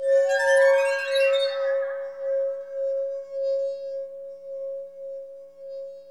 WINE GLAS02L.wav